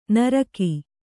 ♪ naraki